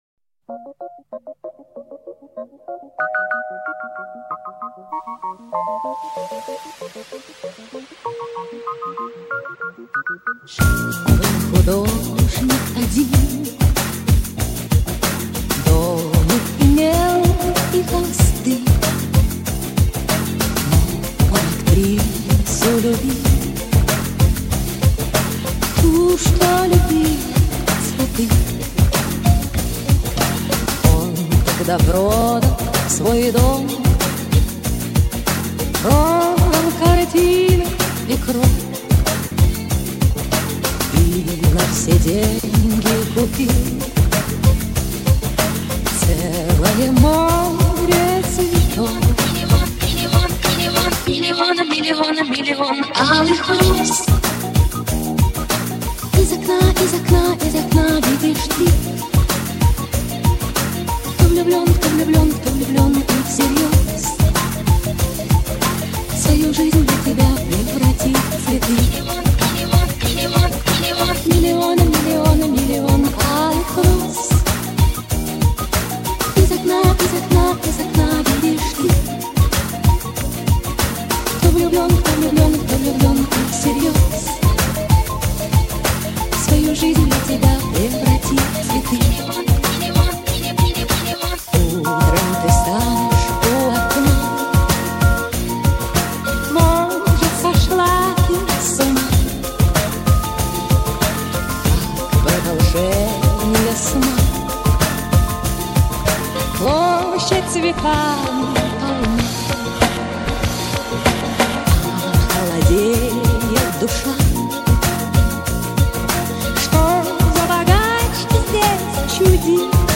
Популярная музыка